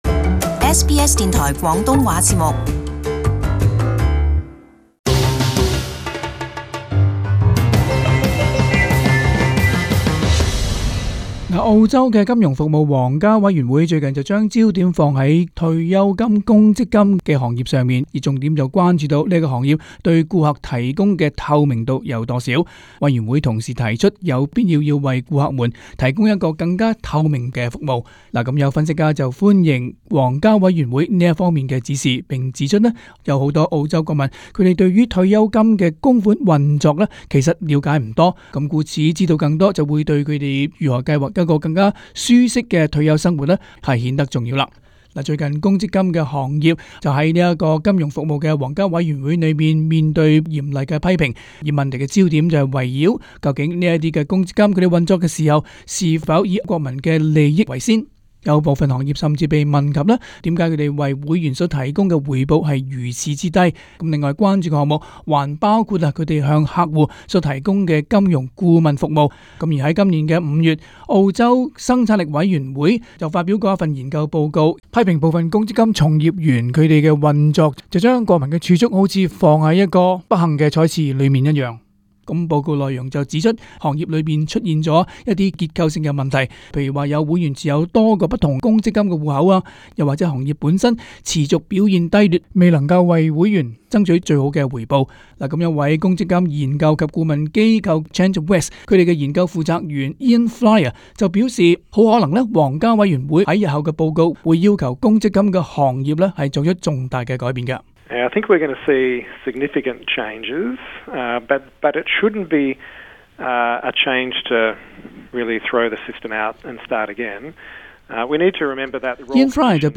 【時事報導】皇家委員會關注部分公積金行業從業員操守